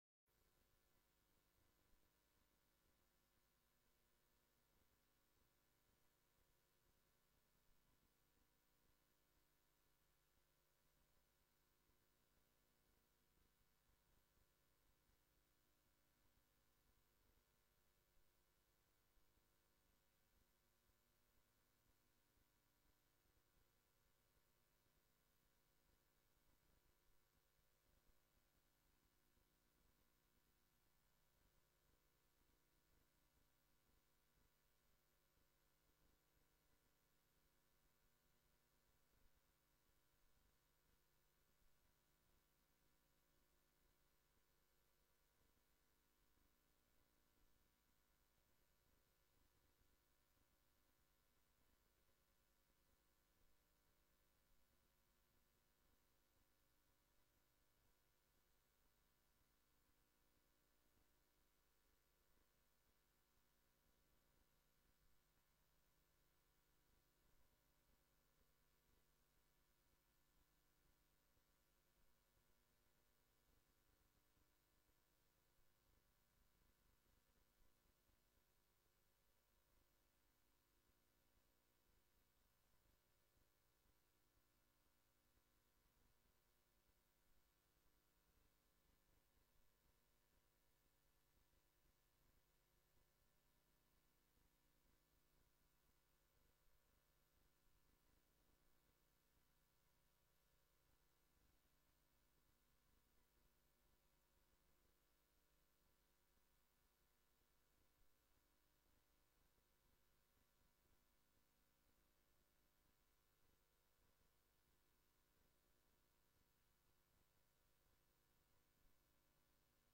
Raadsvergadering 17 mei 2018 20:00:00, Gemeente Oude IJsselstreek
DRU Industriepark - Conferentiezaal